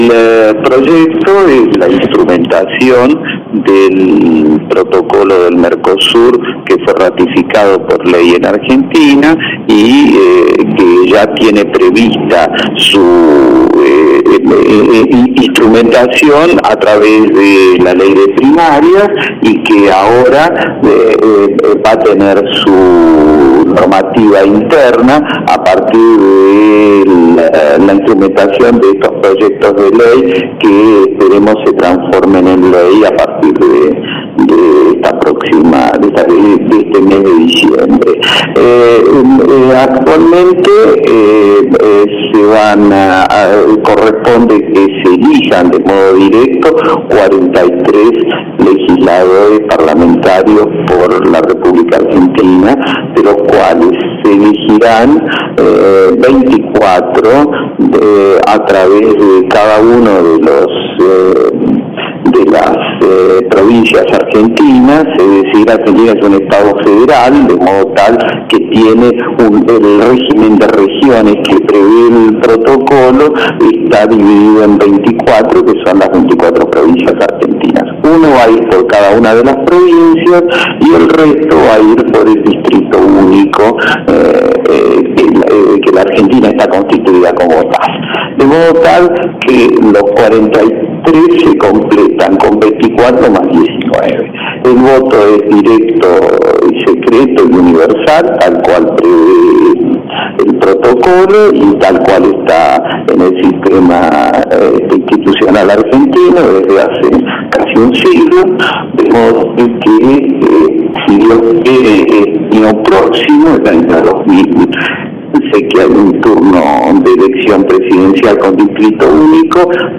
Entrevista com o Parlamentar Jorge Landau